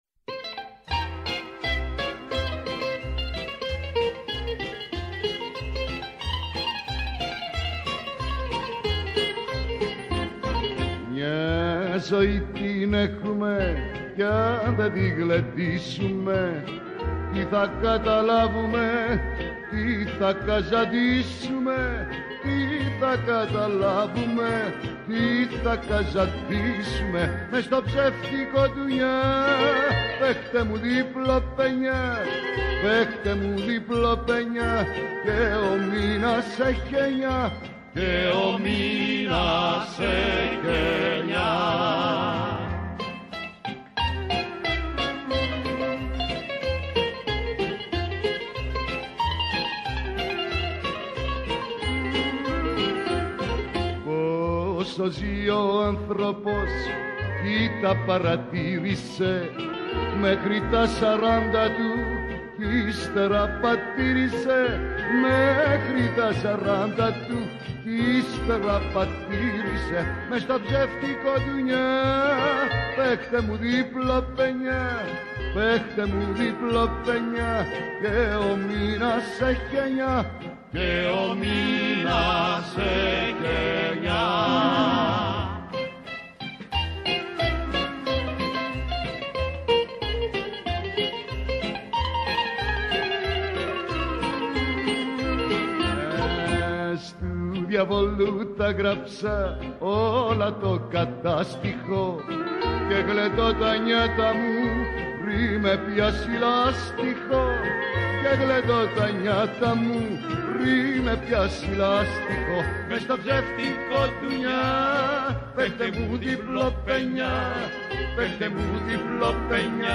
Μια εκπομπή με τραγούδια που αγαπήσαμε, μελωδίες που ξυπνούν μνήμες, αφιερώματα σε σημαντικούς δημιουργούς, κυρίως της ελληνικής μουσικής σκηνής, ενώ δεν απουσιάζουν οι εκφραστές της jazz και του παγκόσμιου μουσικού πολιτισμού.